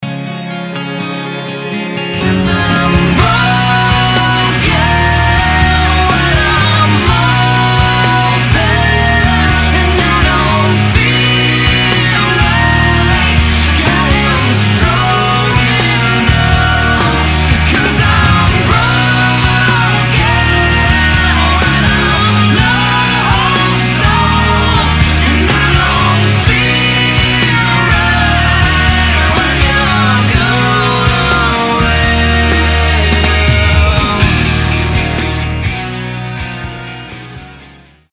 • Качество: 320, Stereo
мужской вокал
дуэт
Alternative Rock
красивый женский вокал
post-grunge
Symphonic Rock